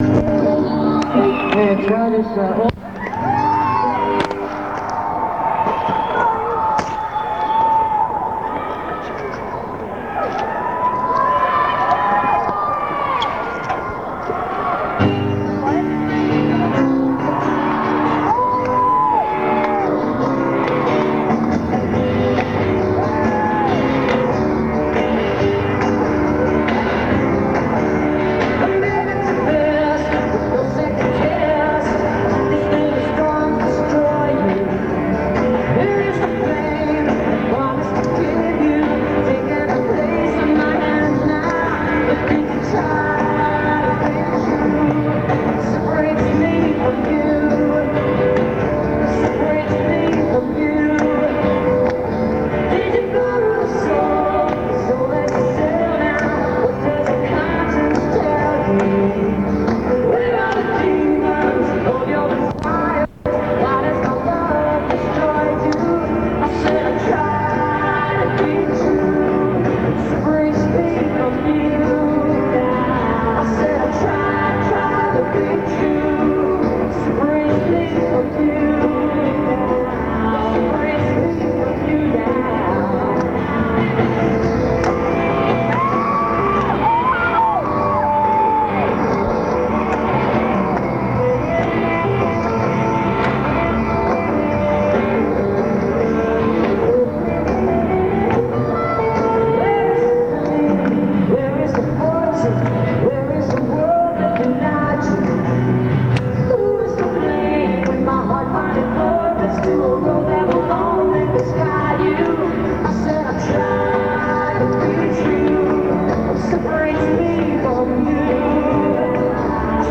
(band show)